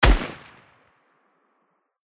Sound Buttons: Sound Buttons View : Fortnite Scar Shot
ar-epic-shot-1.mp3